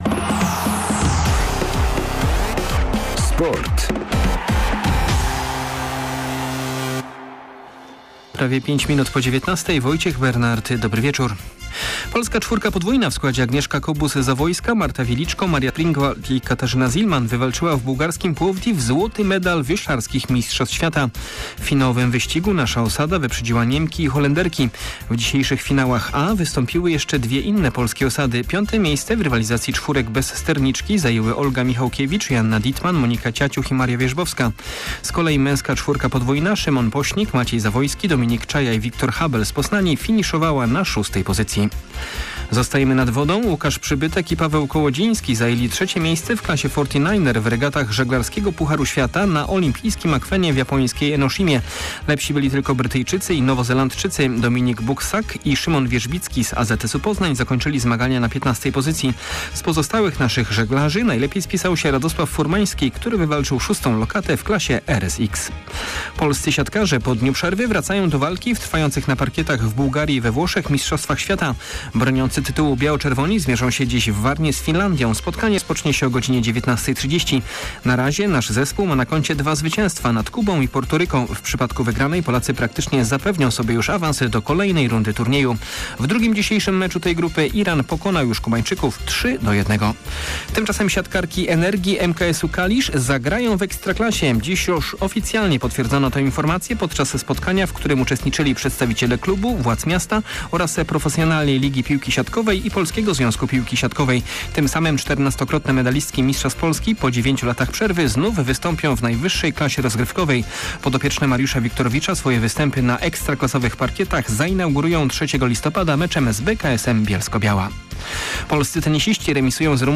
15.09. SERWIS SPORTOWY GODZ. 19:05